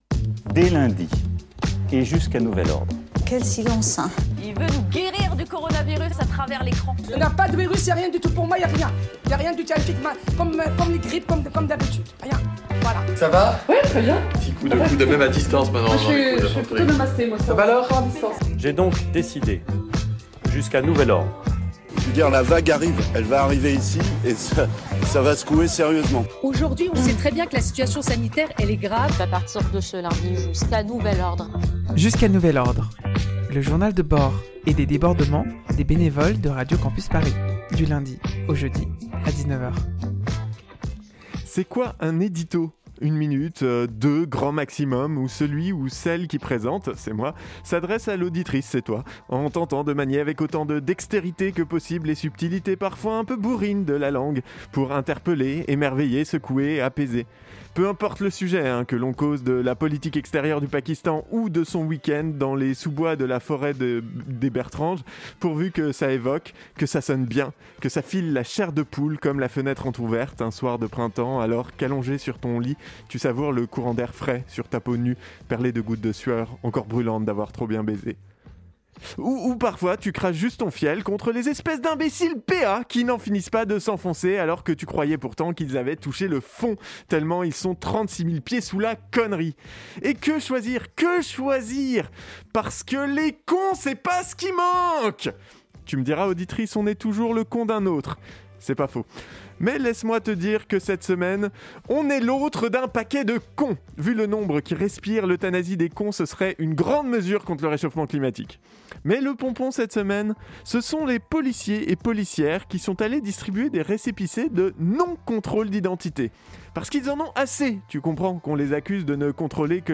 Ce soir, exceptionnellement nous revenons en présentiel !